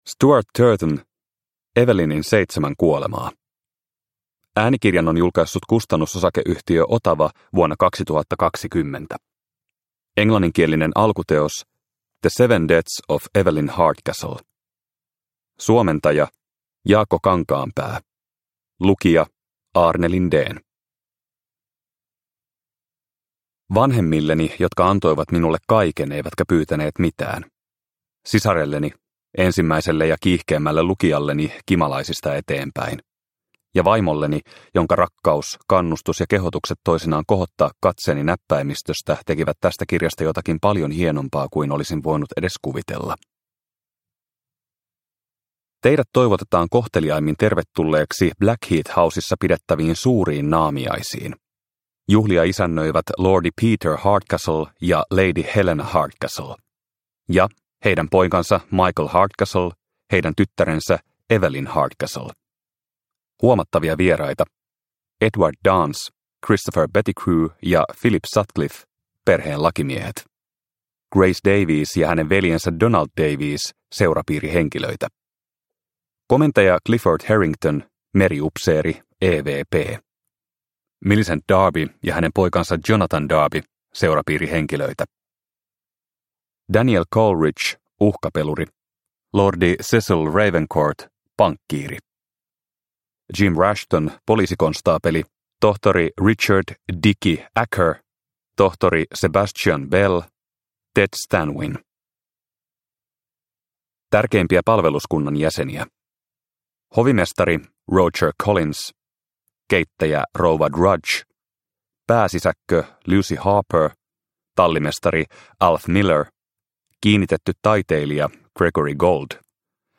Evelynin seitsemän kuolemaa – Ljudbok – Laddas ner